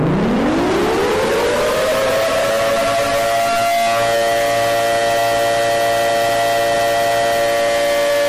Alarm Very Loud Bouton sonore